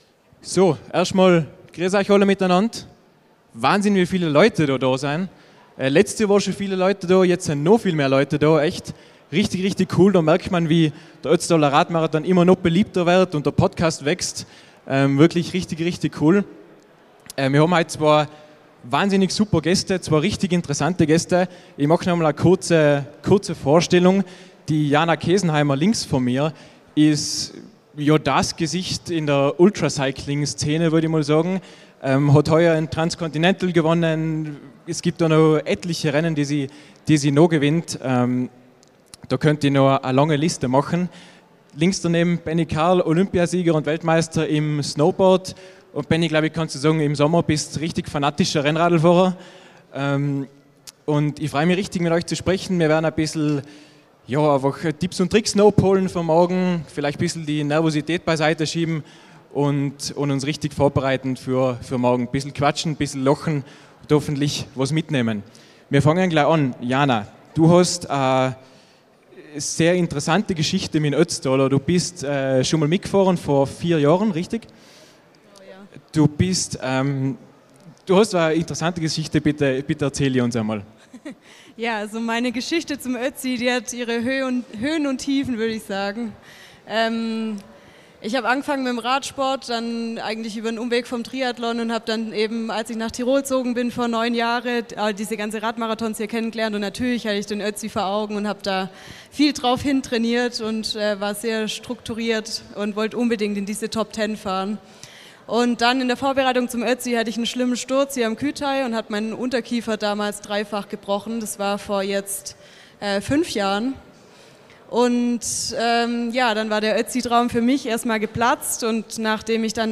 #18 - Live aus Sölden ~ Der Ötztaler Radmarathon Podcast - Ride hard & dream on Podcast
Live-Podcast zum Nachhören: auf der Bühne und mit reichlich Publikum sprechen